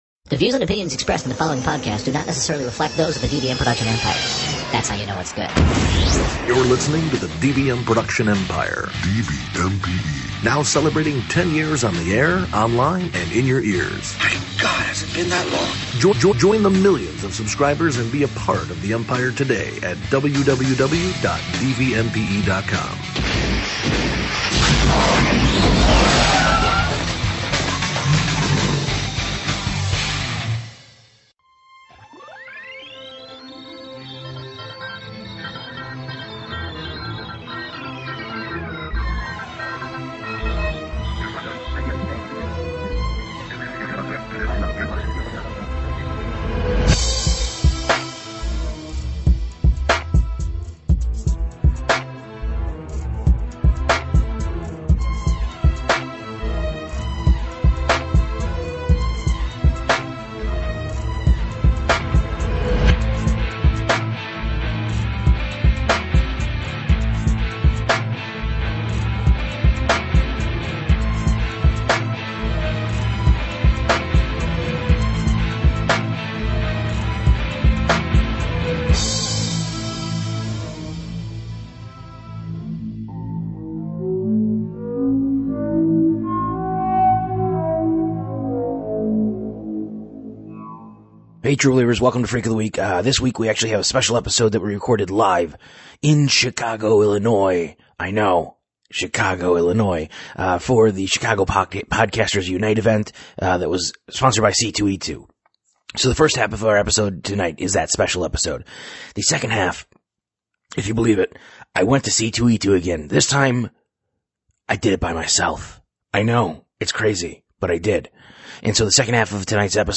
The first half is a live episode recorded as part of the C2E2 Chicago Podcasters Unite event at the Beat Kitchen.
The second half is a series of interviews from the floor of C2E2 this year.